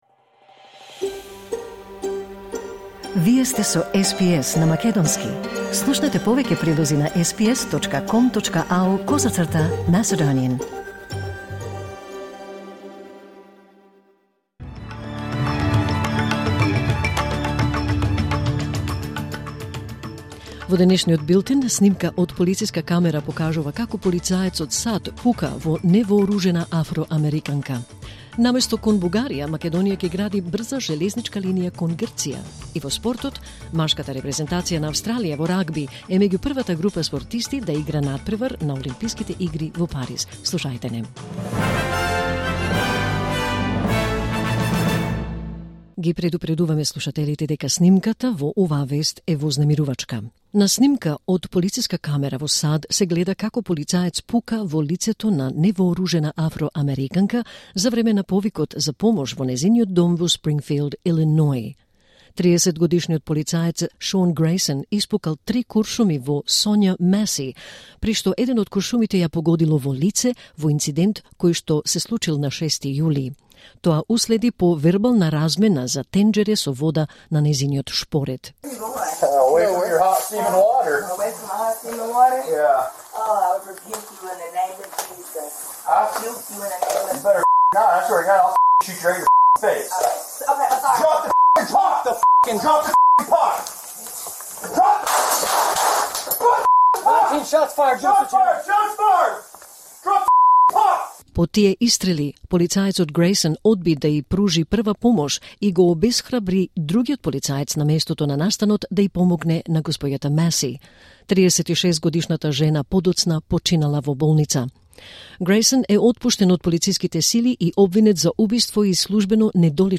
Вести на СБС на македонски 24 јули 2024